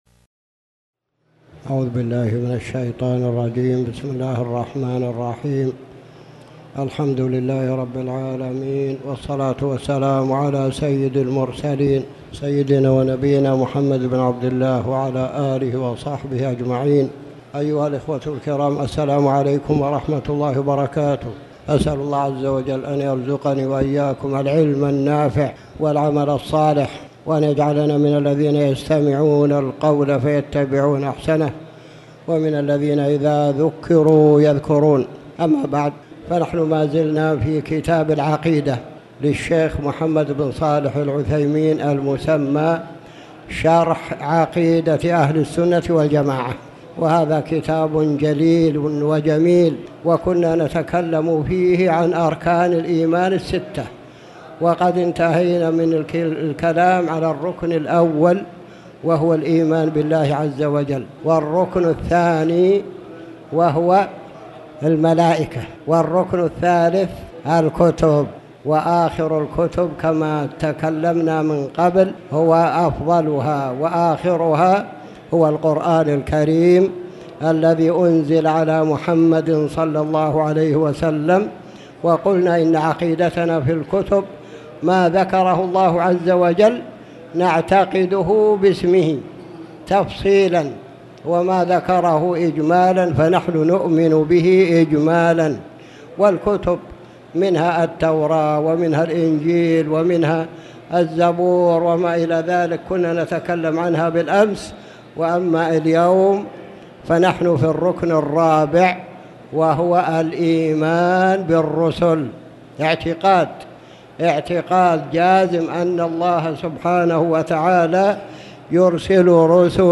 تاريخ النشر ٢٥ صفر ١٤٣٩ هـ المكان: المسجد الحرام الشيخ